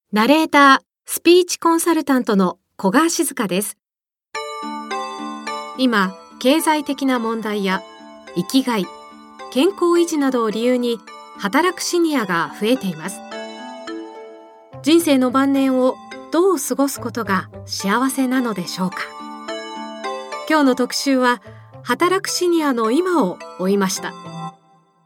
音声サンプル
ラジオアナウンサー時代に培った、ニュース、通販、インタビュー番組での読みや語りを活かしつつ、「言葉」に込められた「想い」をどうやったら伝えられるか日々考えながらナレーションしています。”耳で聞いて分かりやすい”を常に意識しています。